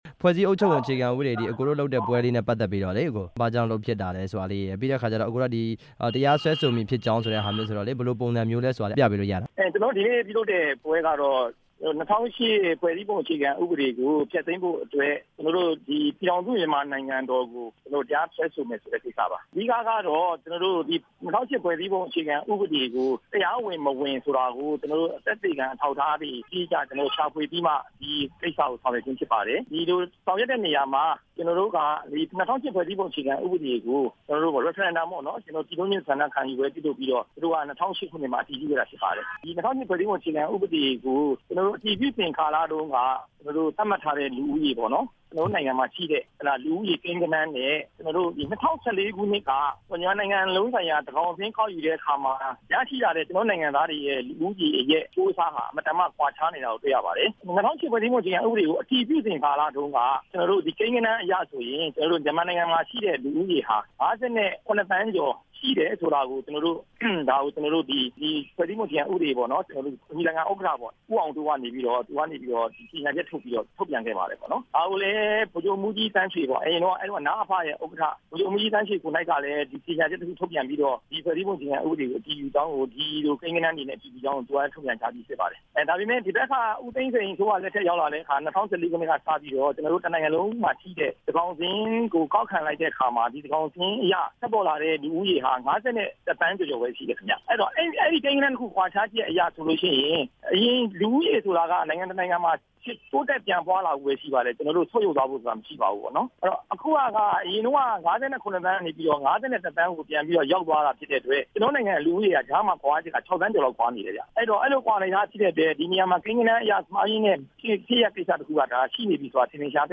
၂၀၀၈ ဖွဲ့စည်းအုပ်ချုပ်ပုံ မှား ယွင်းမှု တရားစွဲရေး မေးမြန်းချက်